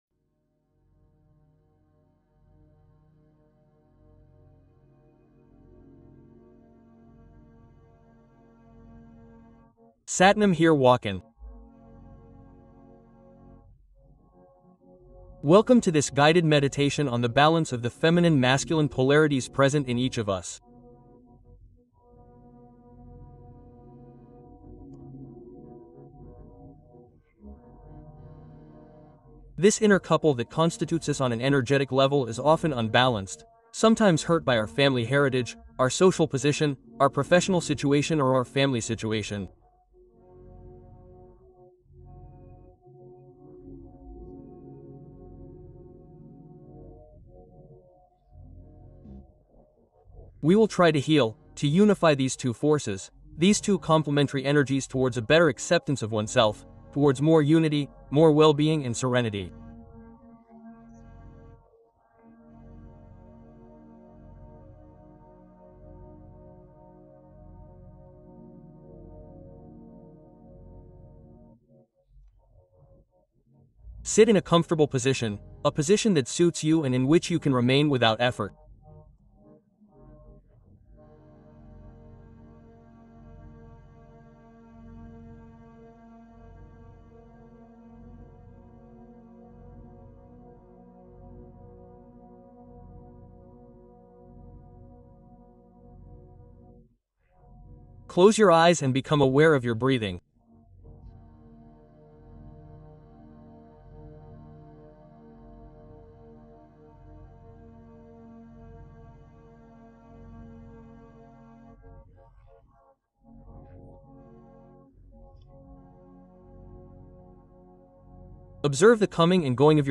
Soulager la douleur : relaxation profonde et écoute corporelle